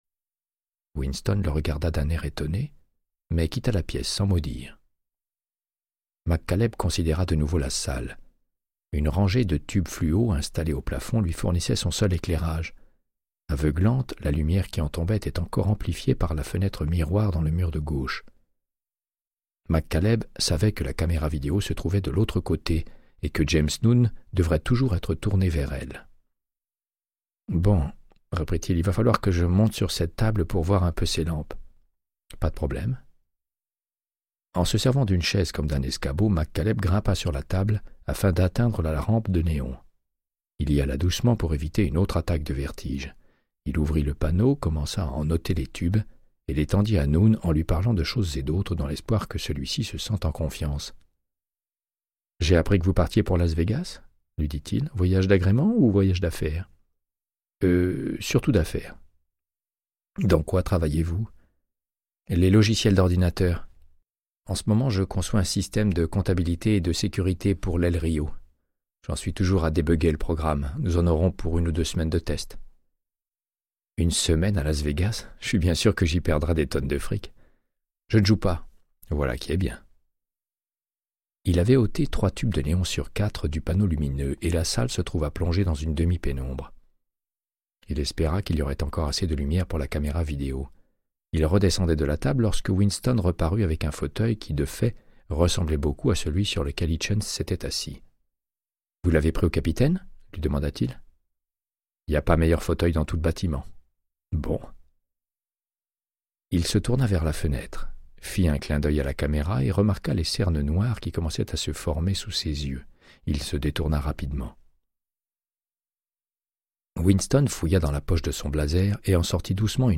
Audiobook = Créance de sang, de Michael Connellly - 65